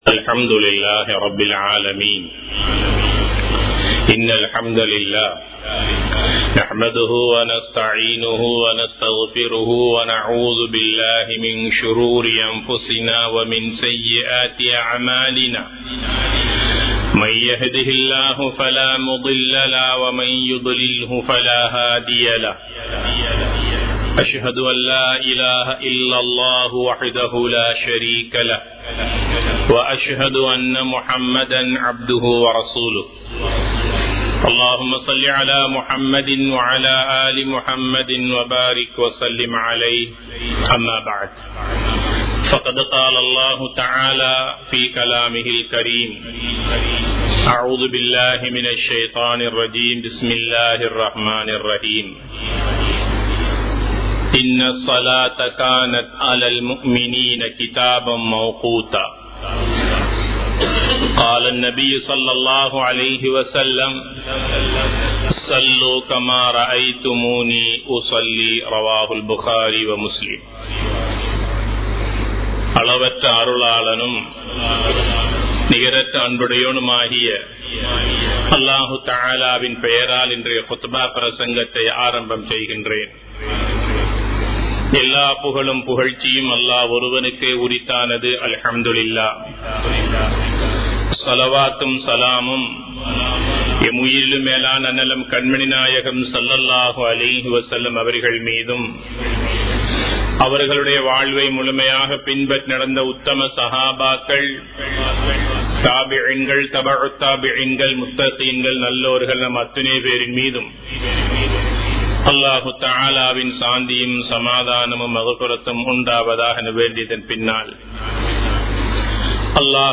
கேடு நிறைந்த தொழுகையாளிகள் | Audio Bayans | All Ceylon Muslim Youth Community | Addalaichenai
Majma Ul Khairah Jumua Masjith (Nimal Road)